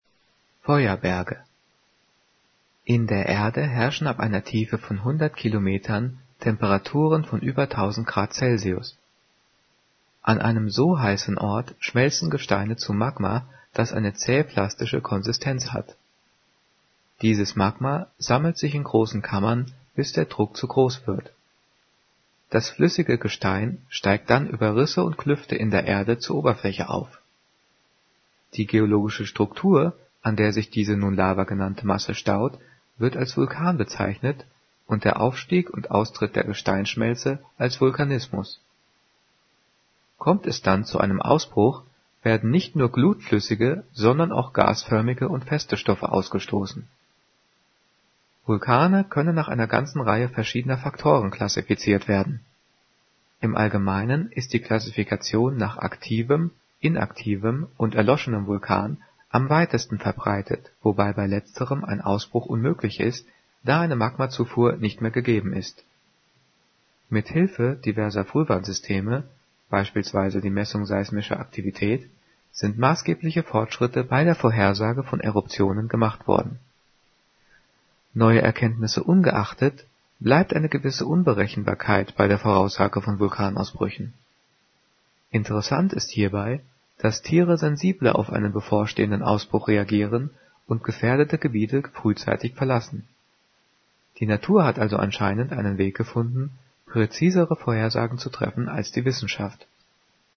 Gelesen:
gelesen-feuerberge.mp3